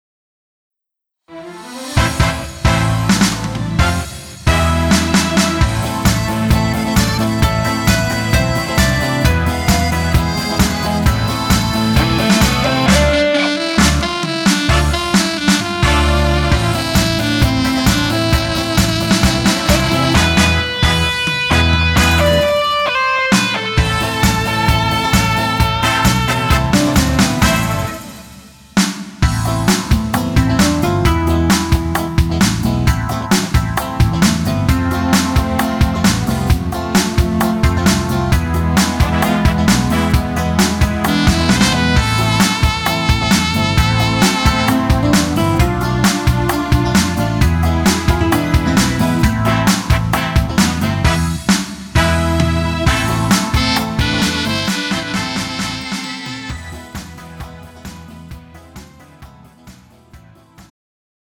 음정 남자키 3:27
장르 가요 구분 Pro MR